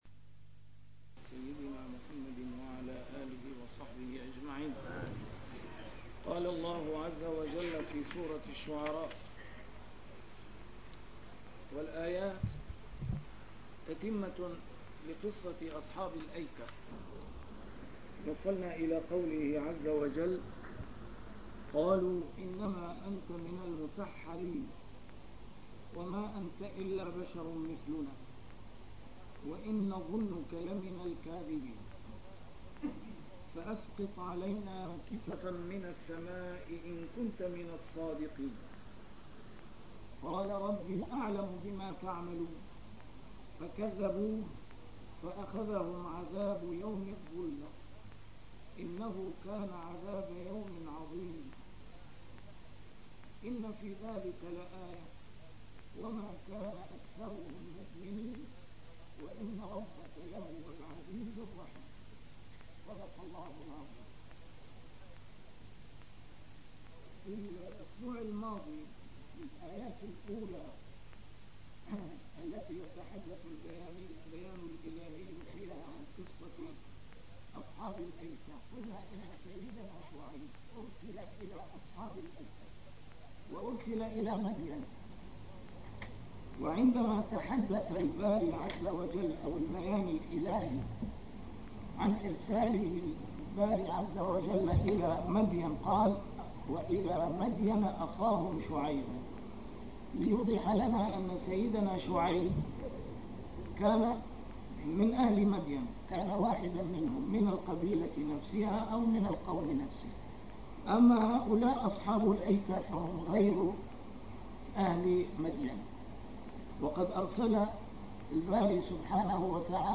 A MARTYR SCHOLAR: IMAM MUHAMMAD SAEED RAMADAN AL-BOUTI - الدروس العلمية - تفسير القرآن الكريم - تسجيل قديم - الدرس 237: الشعراء 185-191